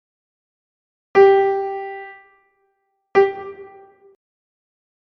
, the first sound is longer than the second one.
notalargacorta.mp3